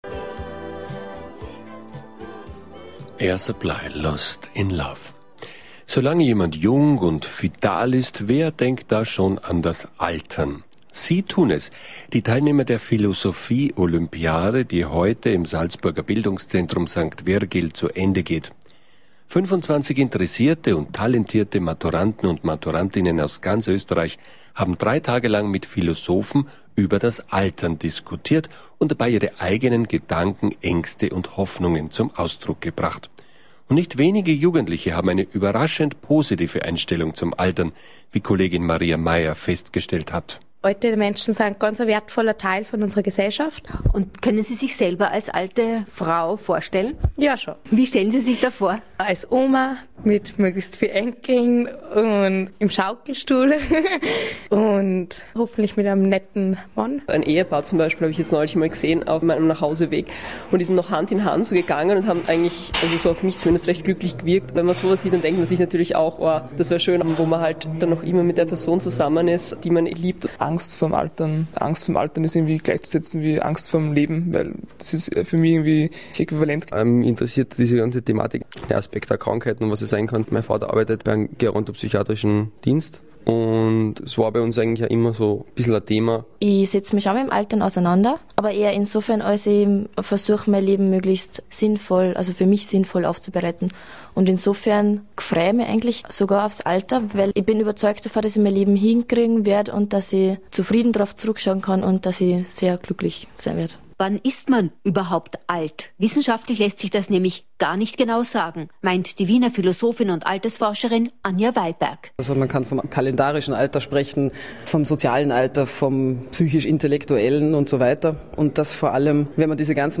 Radiobeitrag-Philolympics-2009.mp3